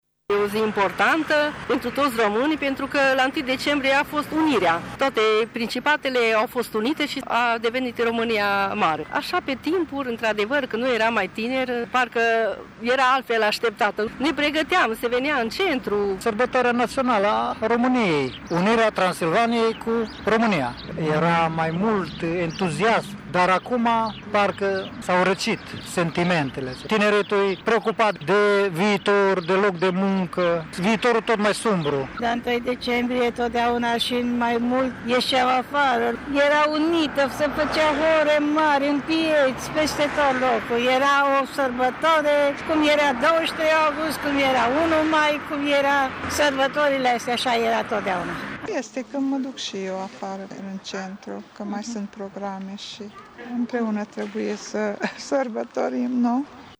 Ziua Națională era sărbătorită cu mai mult entuziasm pe vremuri, spun mureșenii. Acum lumea e mai preocupată de problemele cotidiene: